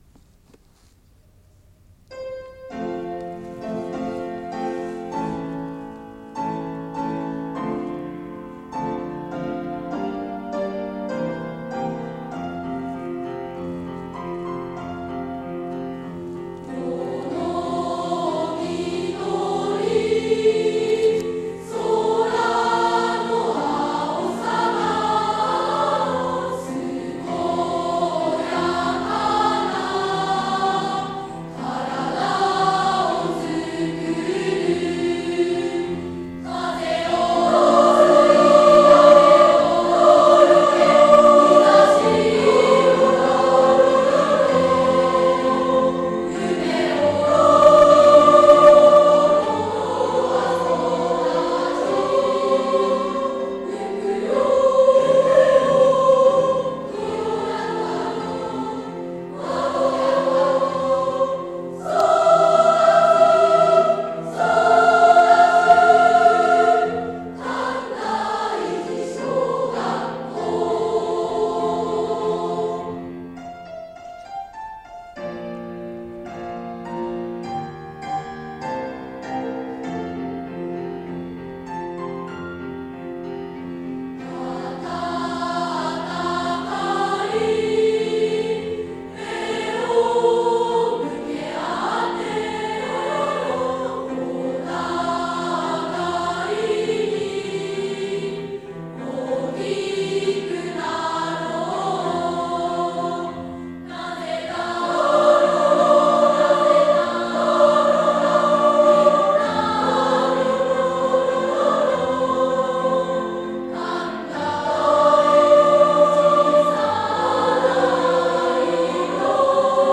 校歌
校歌（平成28年度6年生）ver.